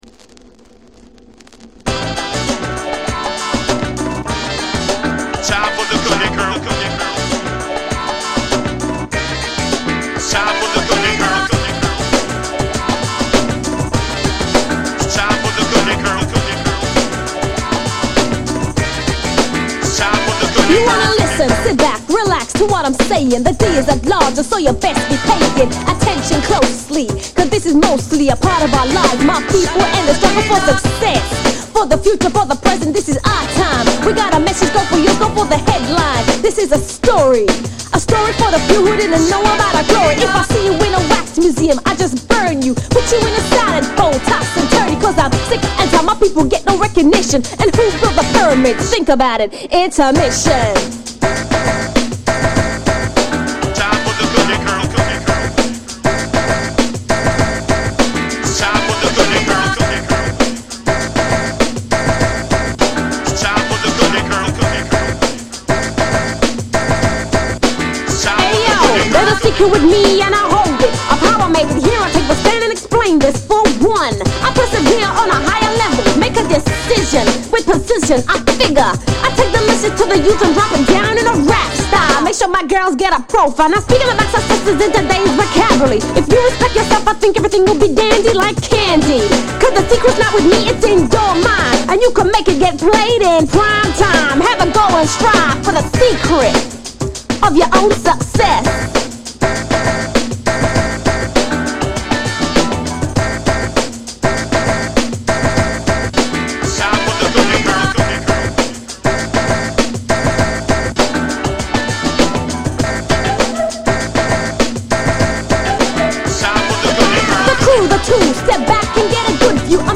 > HIPHOP/R&B